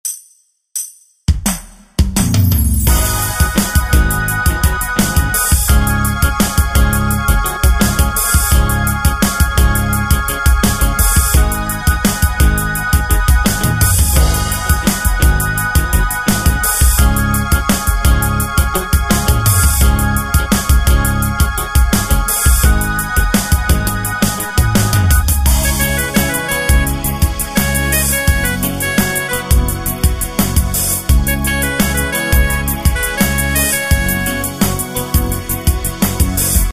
Tempo: 85 BPM.
MP3 with melody DEMO 30s (0.5 MB)zdarma